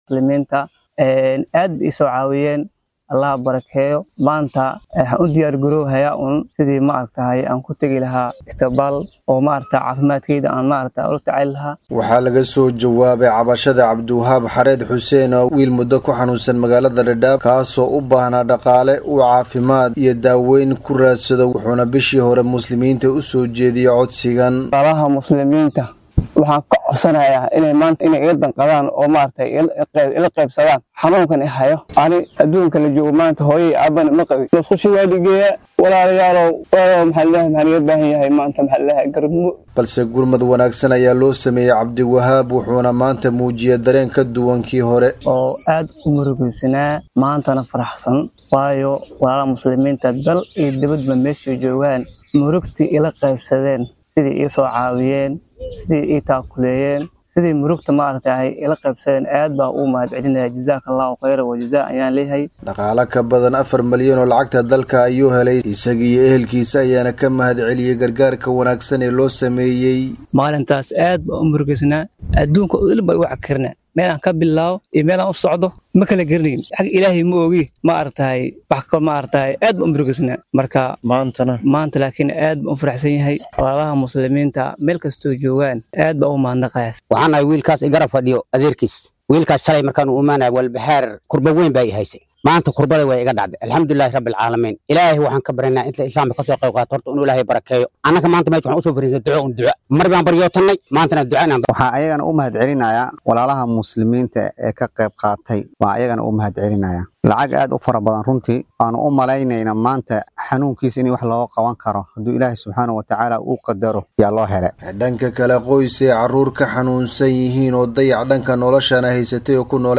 ayaa faahfaahinta qodobkan Dadaab ka soo diray